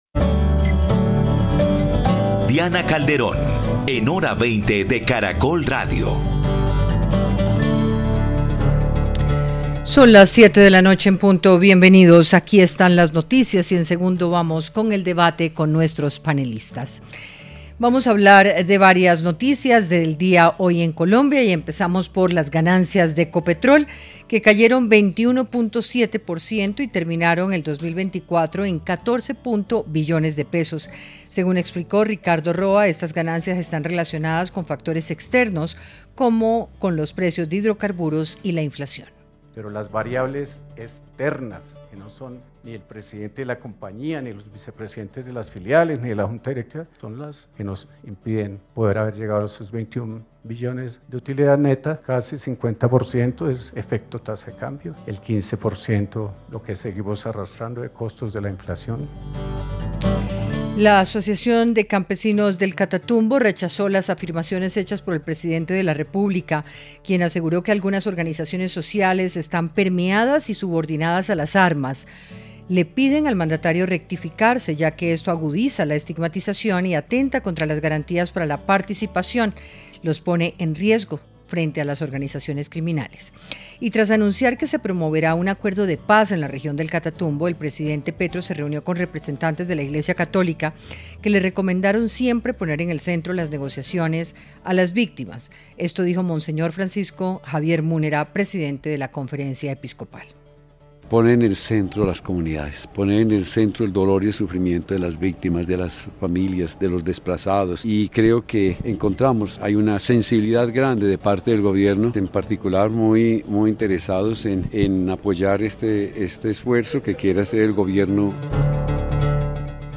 Panelistas analizaron el alcance y lo innovador de la medida. Creen que el plan se tiene que acompañar con una visión integral, interdicción, transformación de la economía regional y garantías de seguridad para los campesinos.